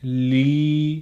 muharni - A Clojure library designed to hack up a web page for studying the Punjabi script and the pronunciation thereof approved for the reading of Sikh sacred texts.